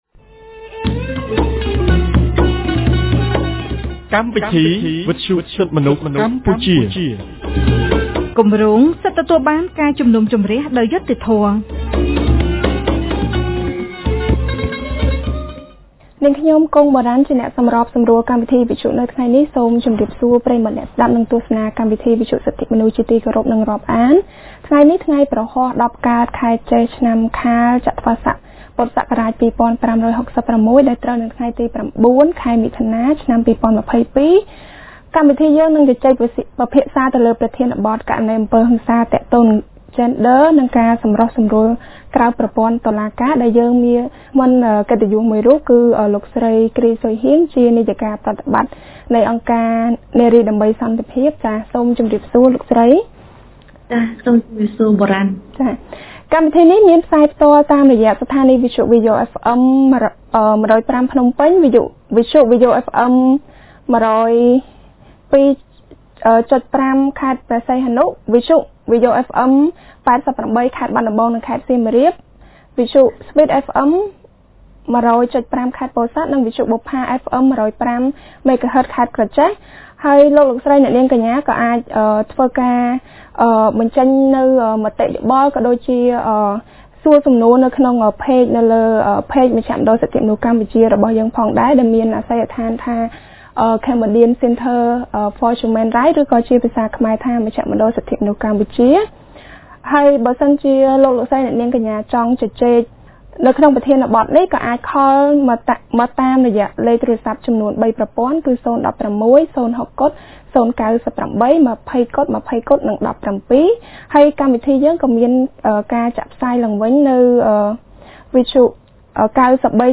radio program